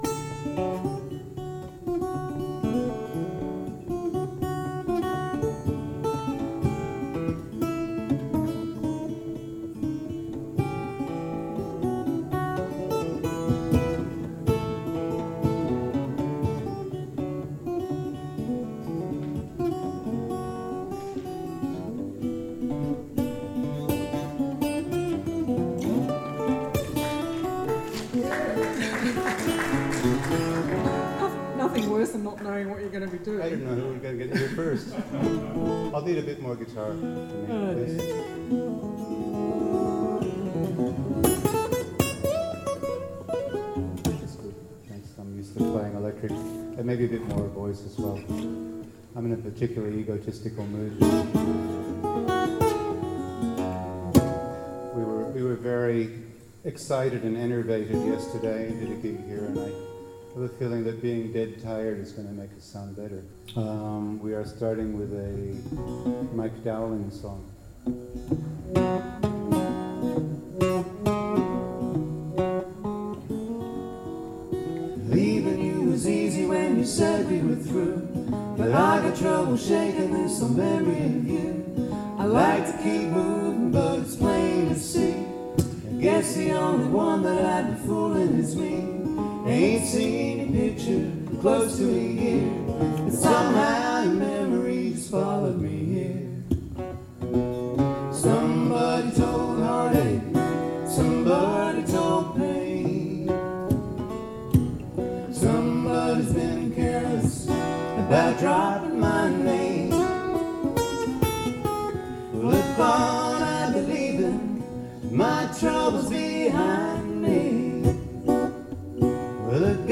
All my guitar recordings are on video at my youtube page Live Performances (all with undersaddle piezo) Some pieces the 2009 Cygnet Folk Festival in Tasmania. Get along little doggies Star of the County Down I've forgotten more than you'll ever know Full concert at the Canberra National Folk Festival 2010 Tamar Valley Festival 2013